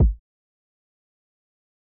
PunchyKick_JJ.wav